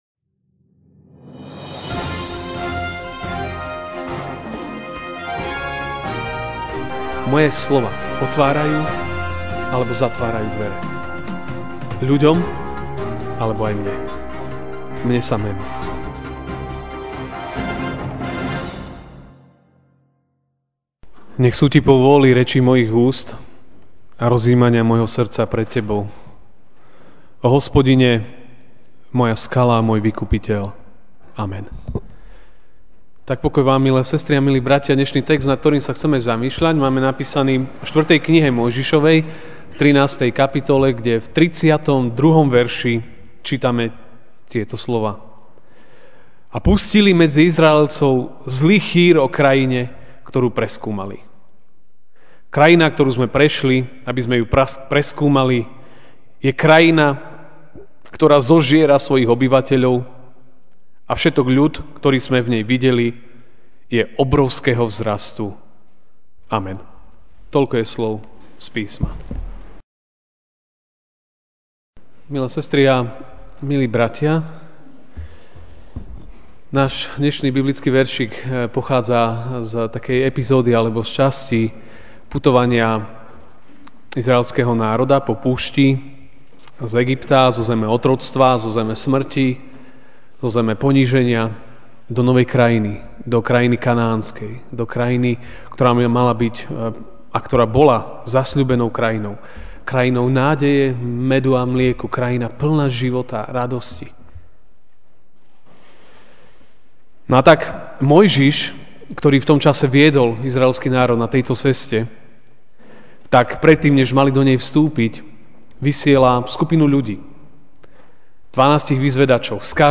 Ranná kázeň: Zvesť, ktorú šírime (4.M. 13, 32)